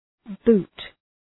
Προφορά
{bu:t}